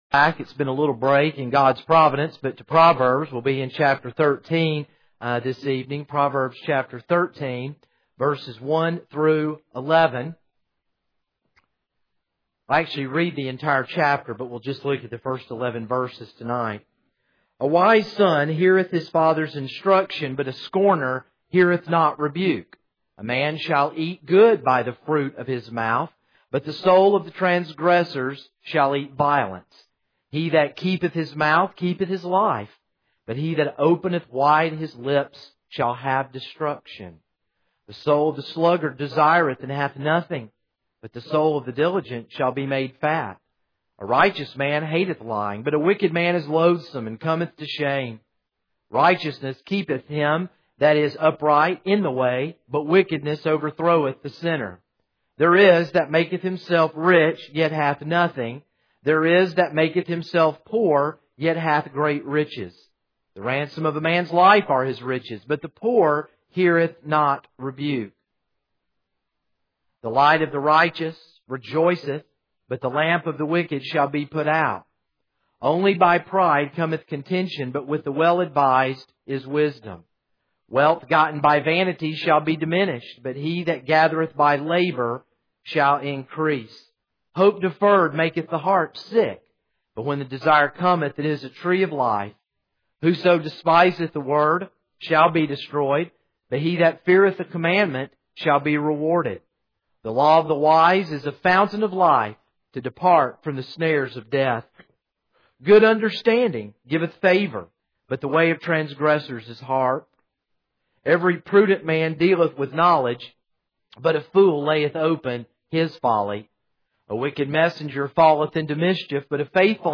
This is a sermon on Proverbs 13:1-11.